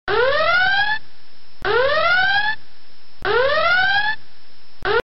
alarm.mp3